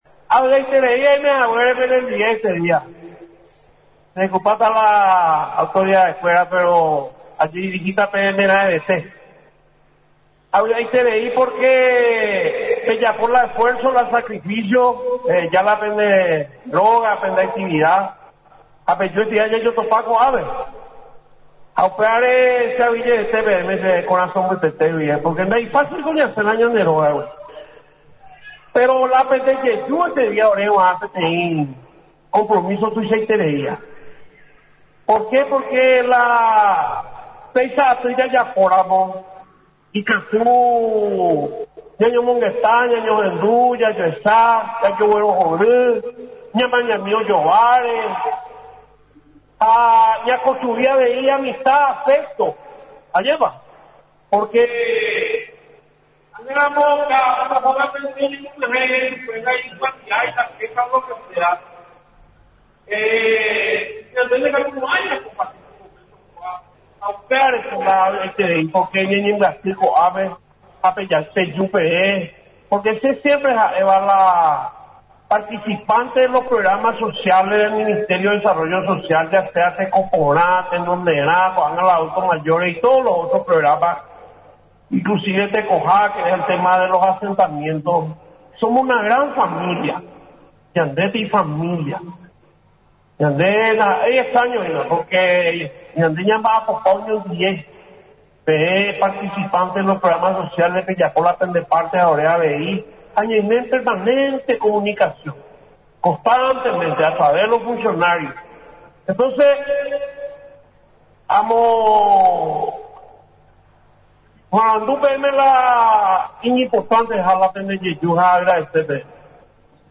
NOTA: TADEO ROJAS-MINISTRO DE DESARROLLO SOCIAL.